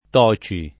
Toci [ t 0© i ]